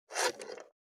472,野菜切る,咀嚼音,ナイフ,調理音,まな板の上,料理,
効果音